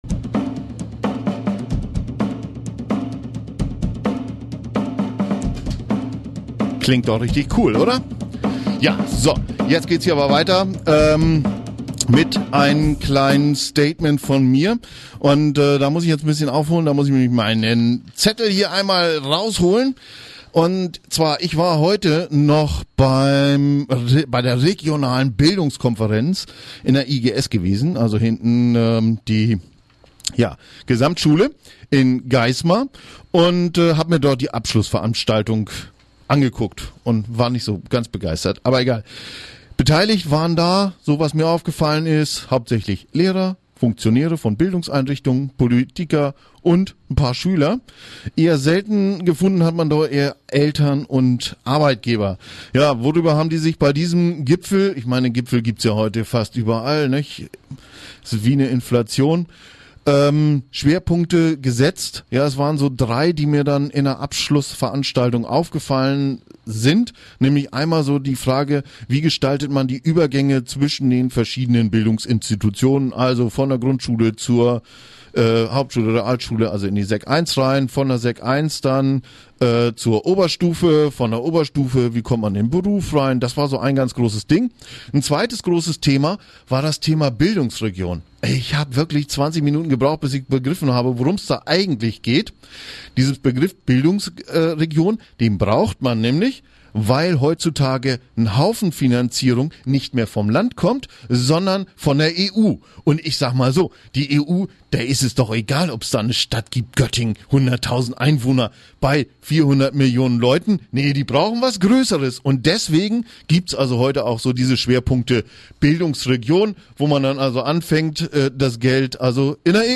Kommentar am 14.7. zur Bildungskonferenz in der Radiosendung "Bürgerstimmen im Göttinger Land" beim Stadtradio Göttingen